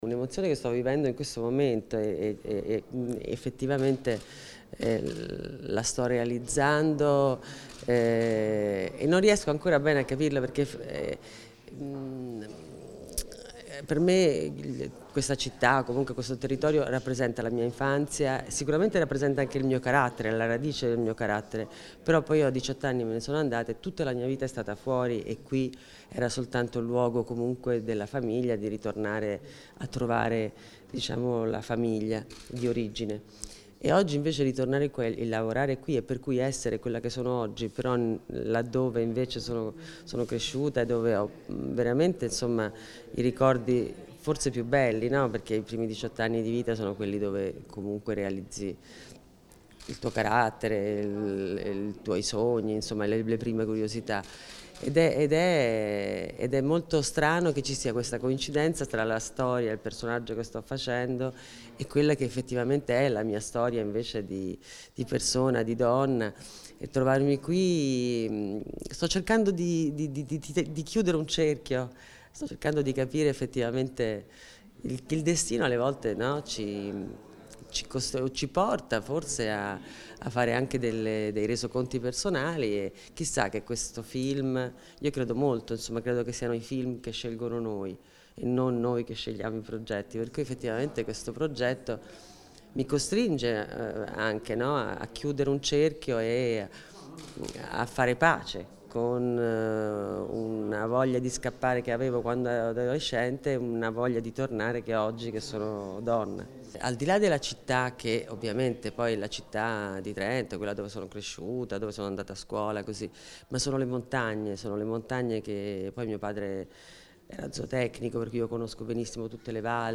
Riprese e interviste a cura dell'Ufficio Stampa -